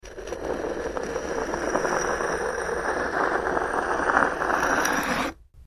Scrape, Stone
StoneScrapesSmooth PE442102
Stone Scrapes; Smooth Cement.